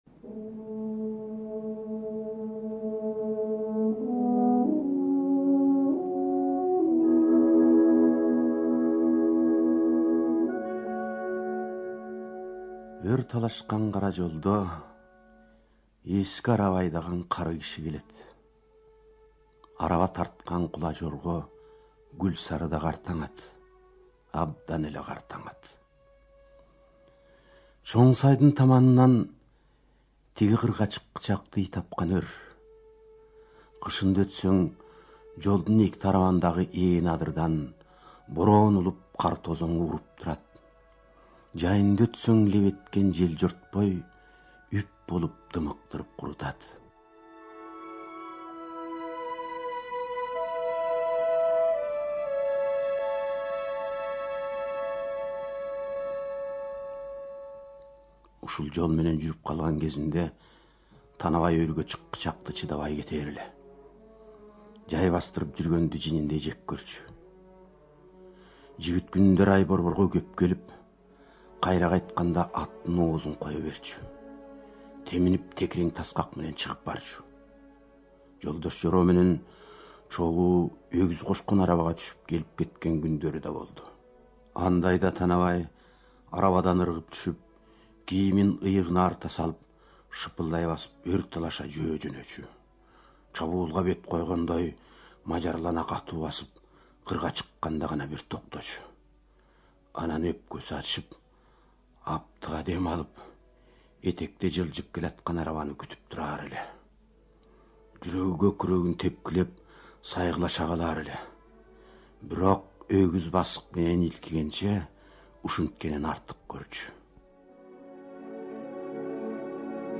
Студия звукозаписиКыргызская Республиканская специализированная библиотека для слепых и глухих